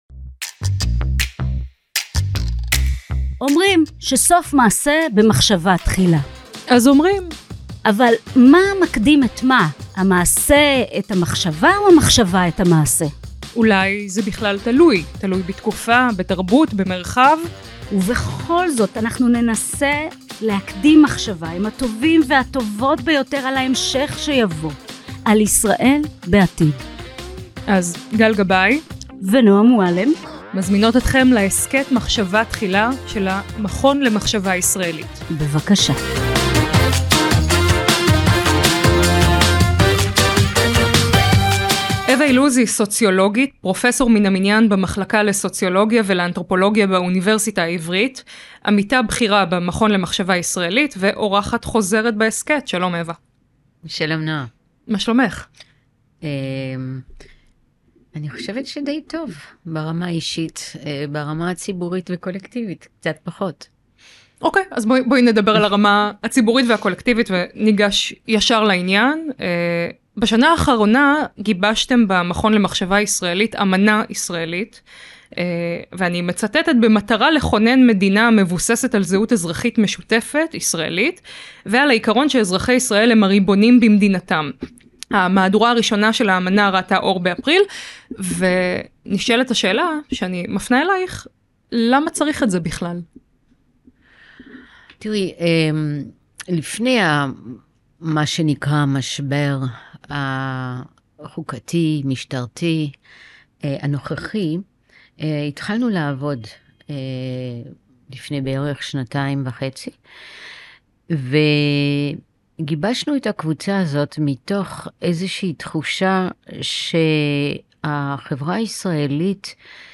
דיונים עם חוקרים העוסקים בבעיות המבניות של ישראל, במורה ליצור חזון חדש המבוסס על אזוריות, חוקה ולאום ישראלי.